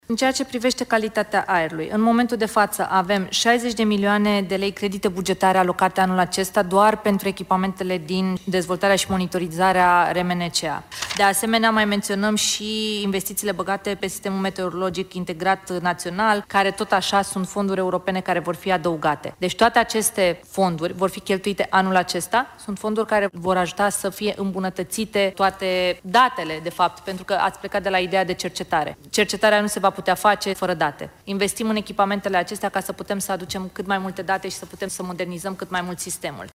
84% din bugetul Ministerului Mediului merge anul acesta pe investiții, a explicat ministra Diana Buzoianu la prezentarea bugetului în comisiile de specialitate din Parlament.